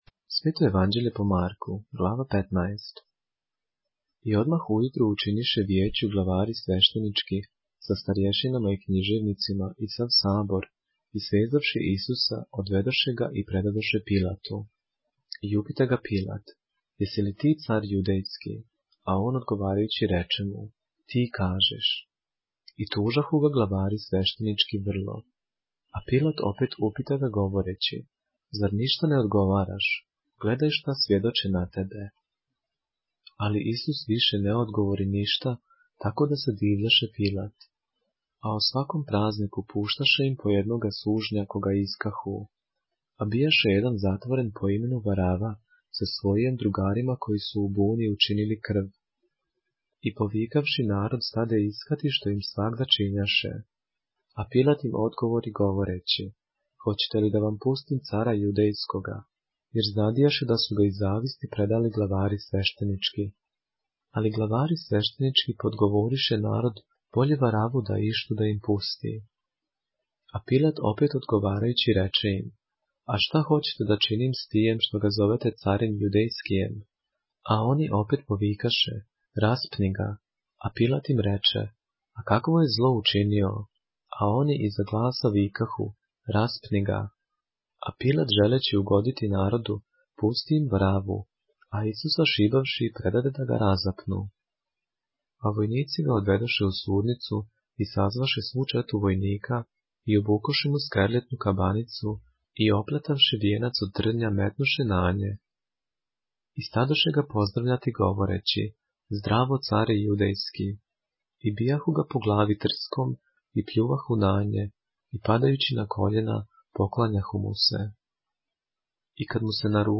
поглавље српске Библије - са аудио нарације - Mark, chapter 15 of the Holy Bible in the Serbian language